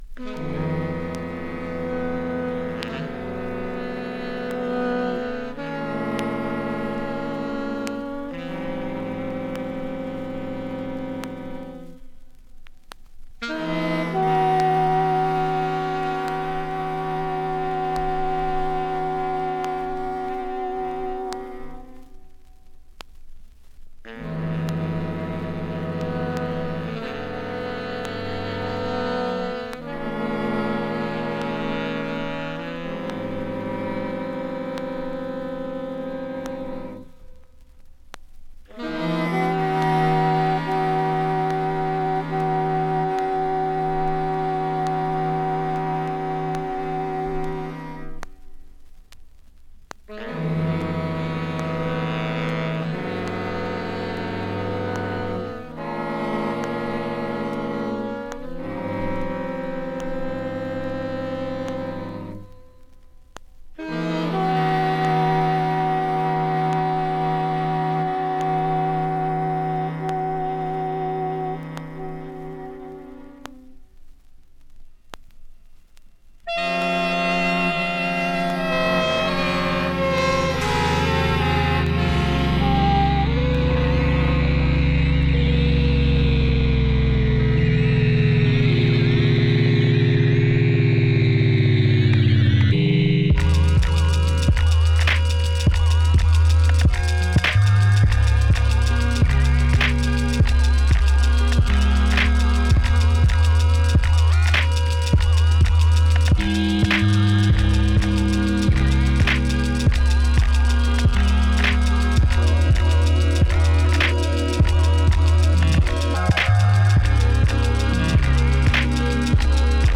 Beats Electronic Funk/Soul